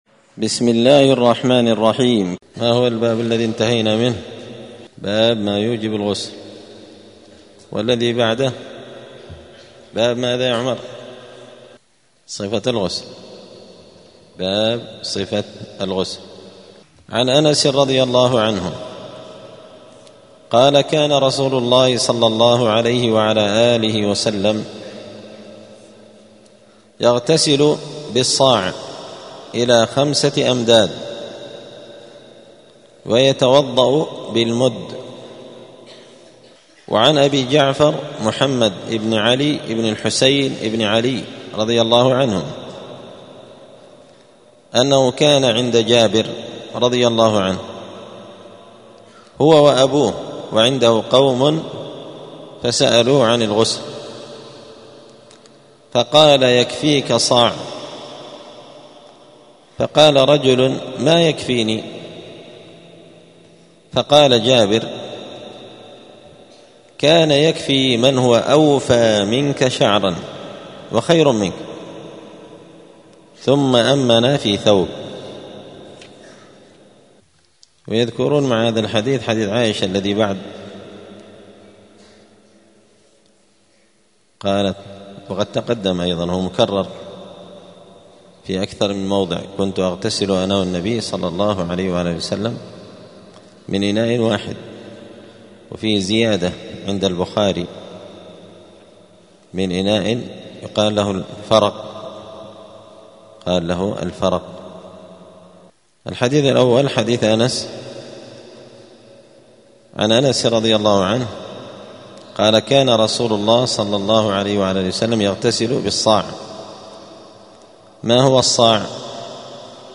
دار الحديث السلفية بمسجد الفرقان قشن المهرة اليمن
*الدرس التاسع والسبعون [79] {باب صفة الغسل مقدار مايغتسل به}*